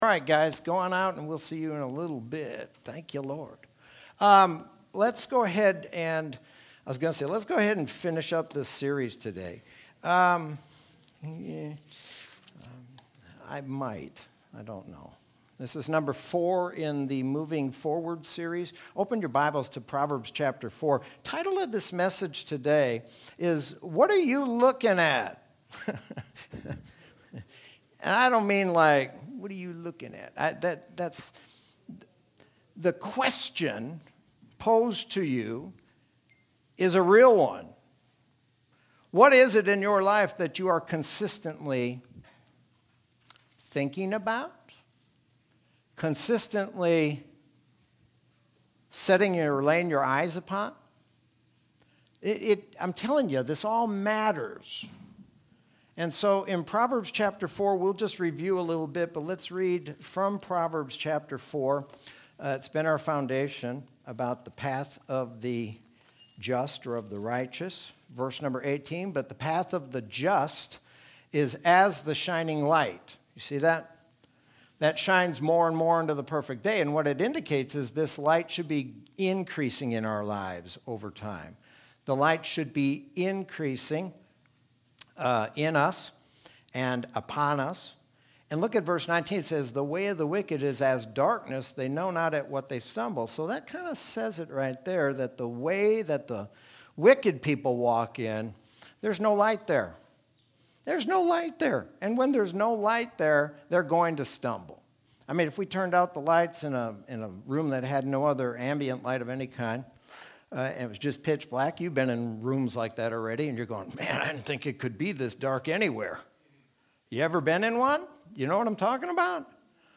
Sermon from Sunday, January 10th, 2021.